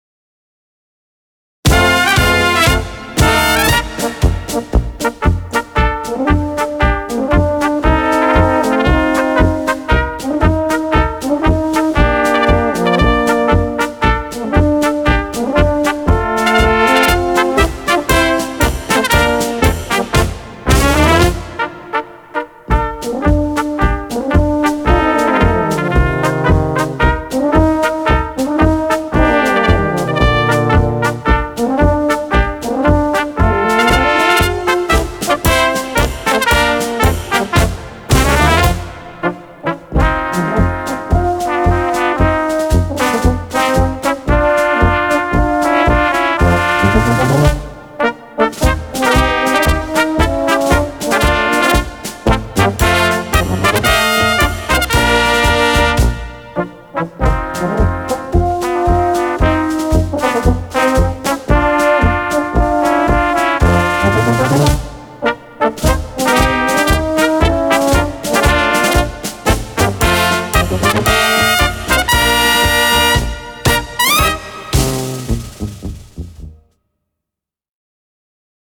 Brass mit Herz und Seele.
Trompete
Posaune/Tenorhorn
Tuba
Schlagzeug